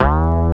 19SYN.BASS.wav